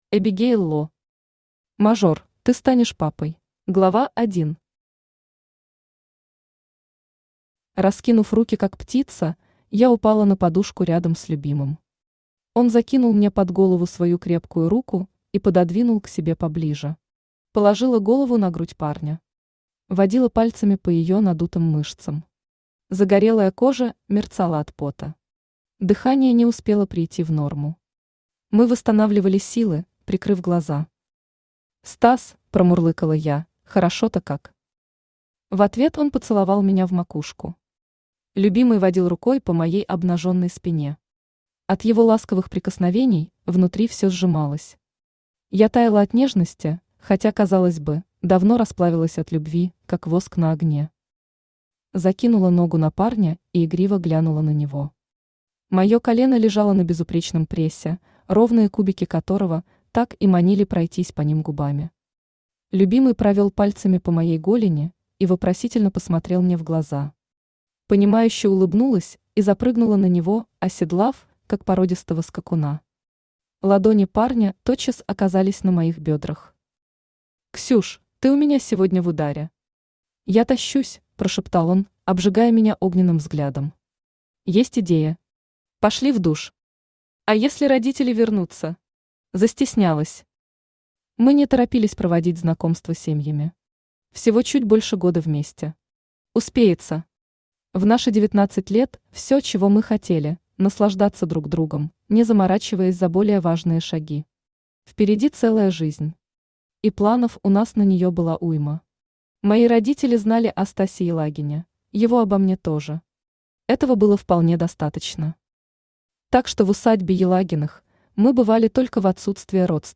Аудиокнига Мажор, ты станешь папой | Библиотека аудиокниг
Aудиокнига Мажор, ты станешь папой Автор Эбигейл Ло Читает аудиокнигу Авточтец ЛитРес.